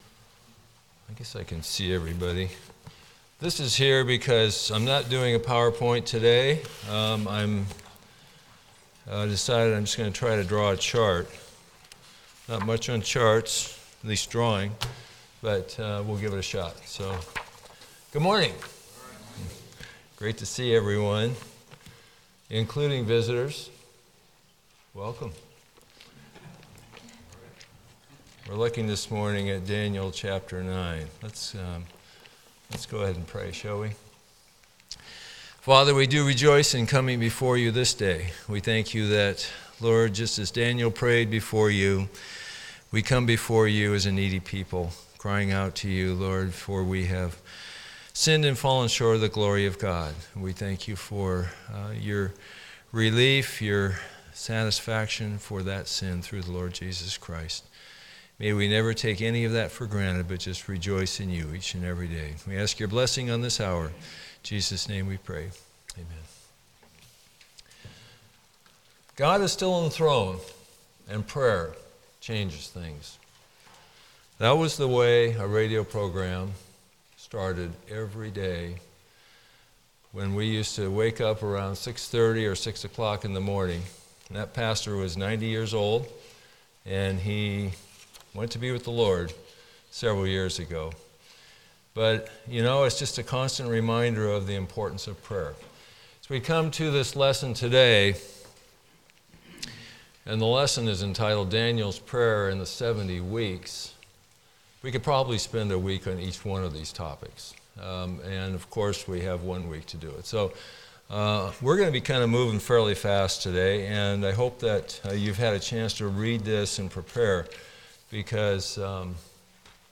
Passage: Daniel 9 Service Type: Sunday School